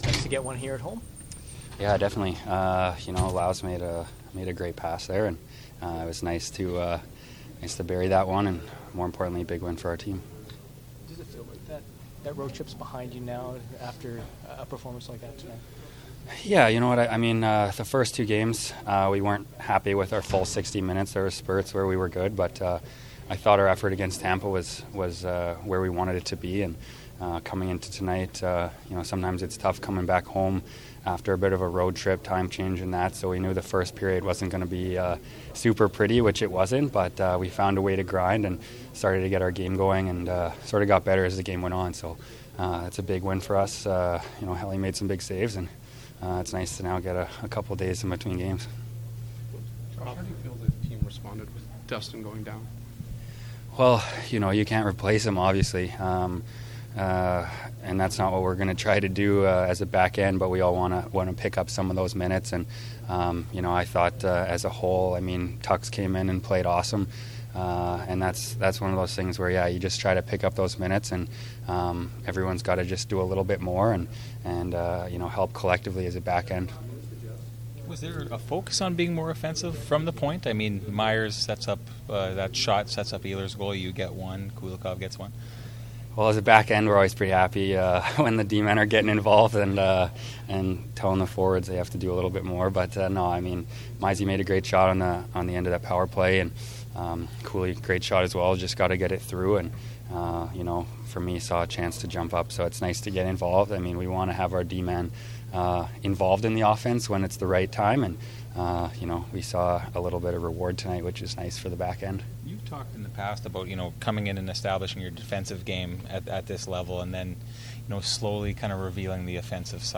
Post-game from the Jets and Canucks dressing rooms as well as from Coach Maurice.